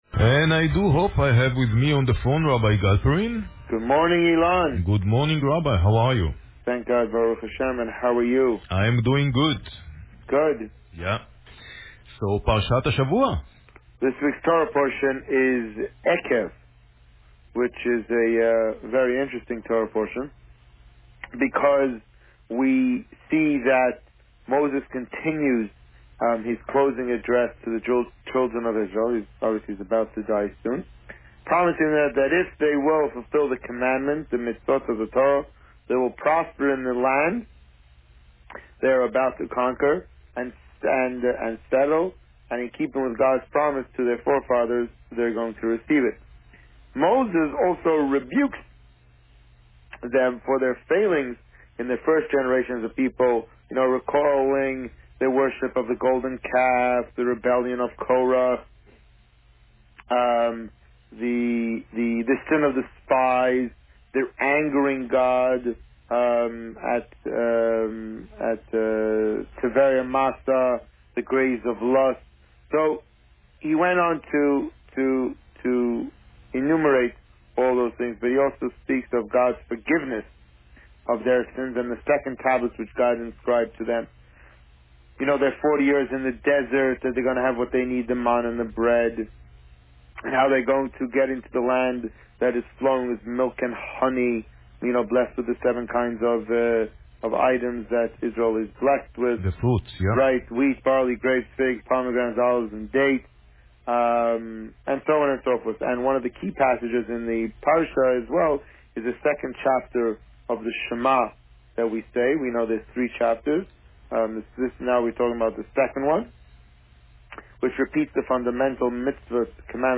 This week, the Rabbi spoke about Parsha Eikev. Listen to the interview here.